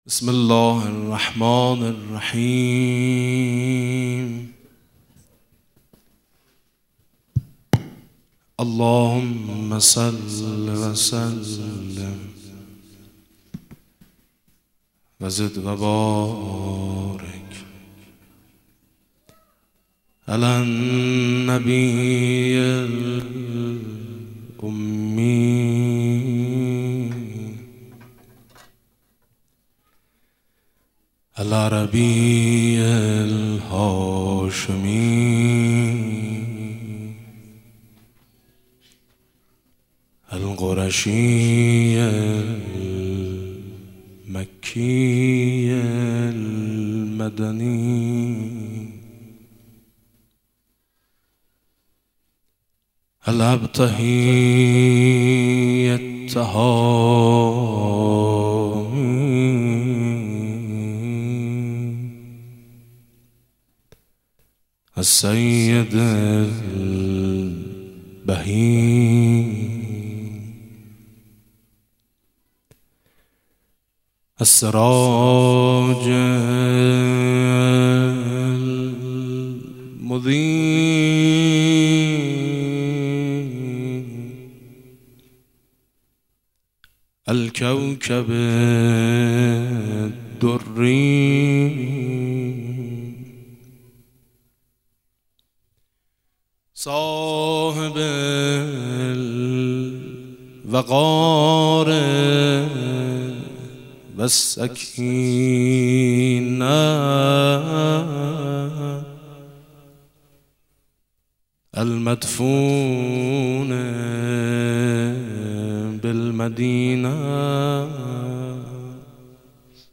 گلچین مولودی مبعث پیامبر